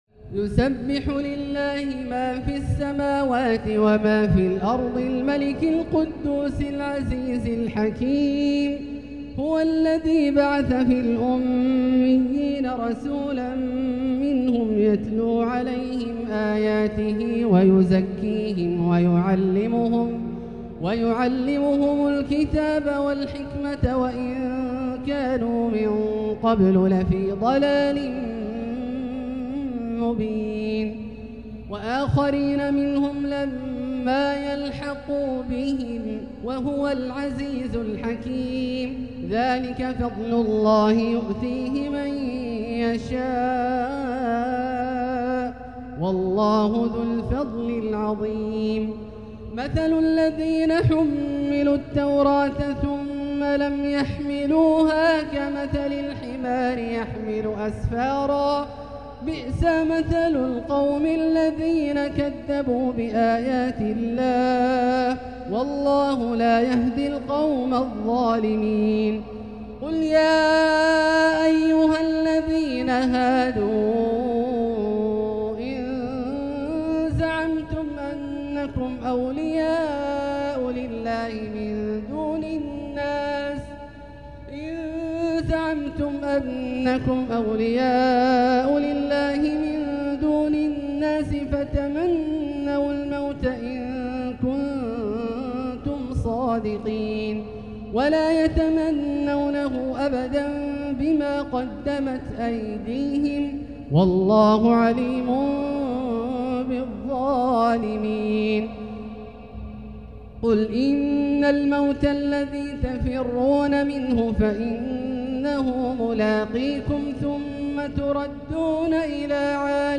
تلاوة ندية لـ سورة الجمعة كاملة للشيخ د. عبدالله الجهني من المسجد الحرام | Surat Al-Jumuah > تصوير مرئي للسور الكاملة من المسجد الحرام 🕋 > المزيد - تلاوات عبدالله الجهني